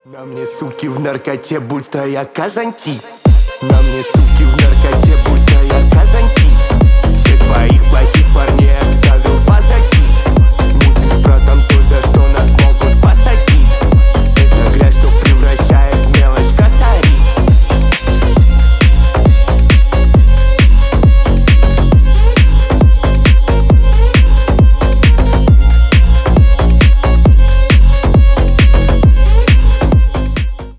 • Качество: 320 kbps, Stereo
Ремикс
клубные
громкие